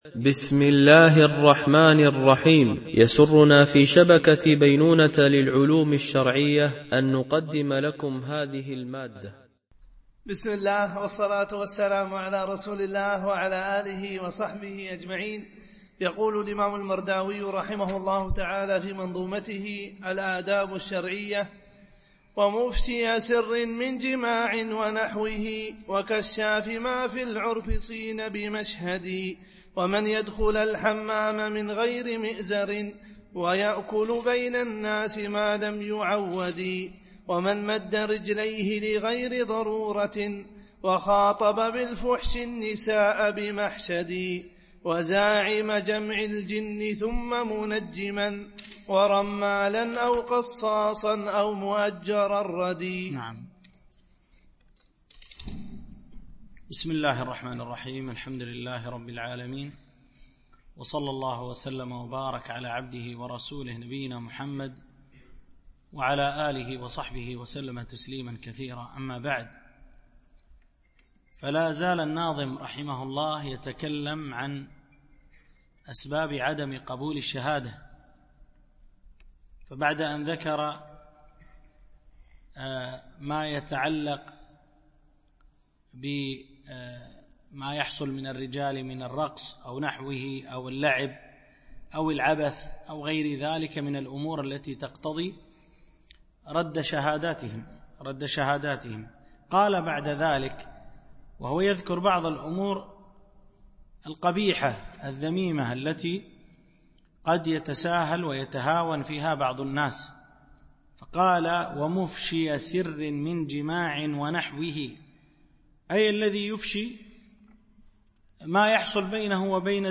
شرح منظومة الآداب الشرعية – الدرس27 ( الأبيات 398-407 )